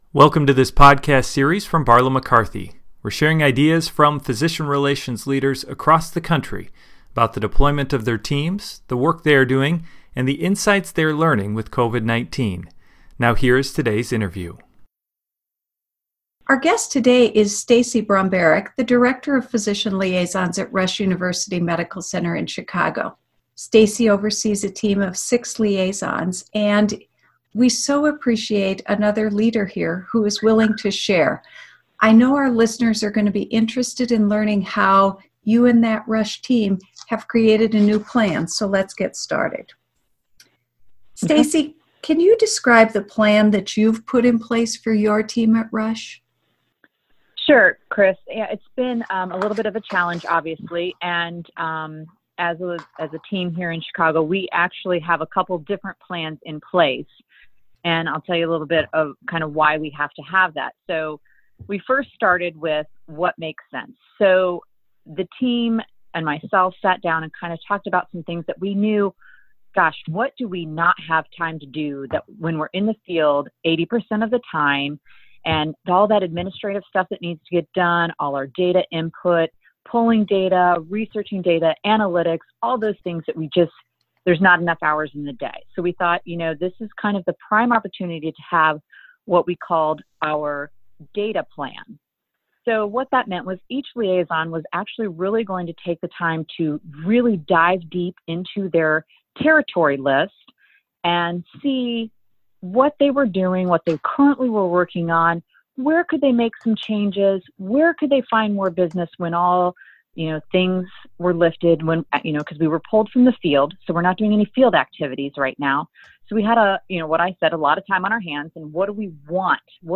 B/Mc Podcast: COVID-19 and Physician Relations – Leadership Interview #3